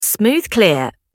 smooth_clear.wav